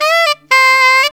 SAX JN LIC00.wav